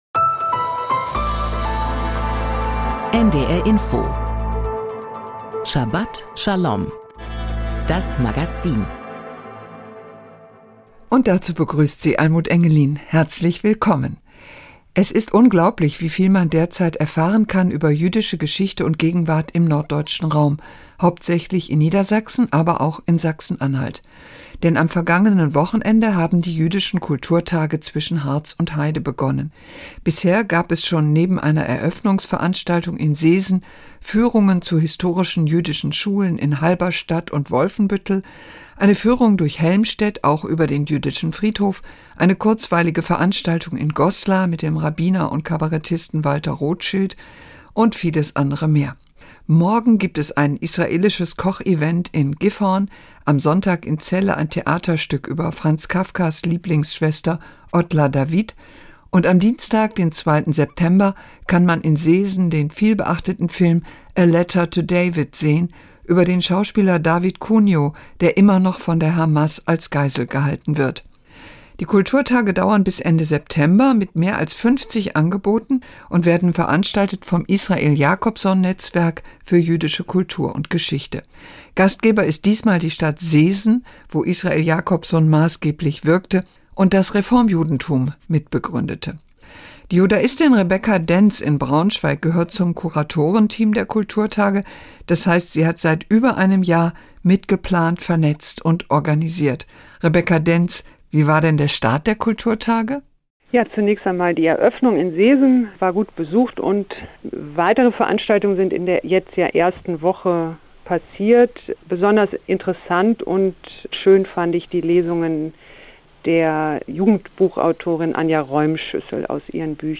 Jüdische Kulturtage zwischen Harz und Heide Interview